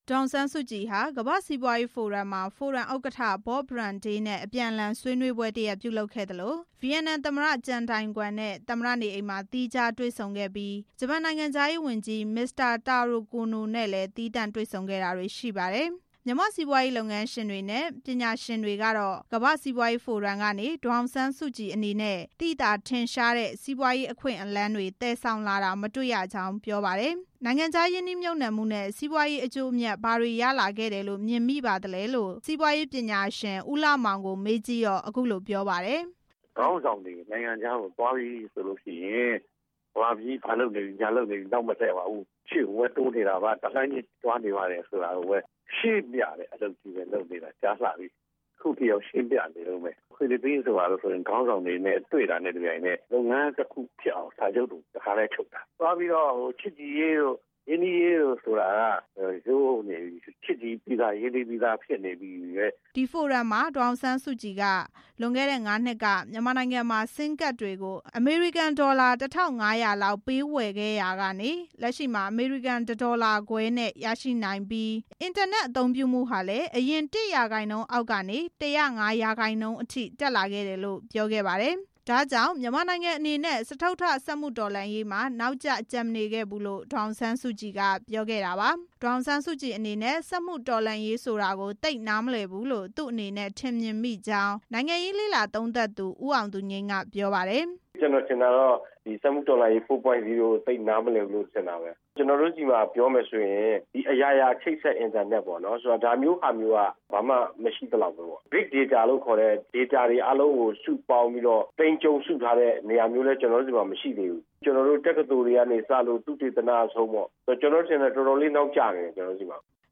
ပြည်တွင်း စီးပွားရေးလုပ်ငန်းရှင်တွေ၊ ပညာရှင်တွေနဲ့ နိုင်ငံရေးလေ့လာသူတွေရဲ့ သုံးသပ်ချက်တွေကို